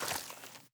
added stepping sounds
Mud_Mono_05.wav